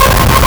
Player_Glitch [6].wav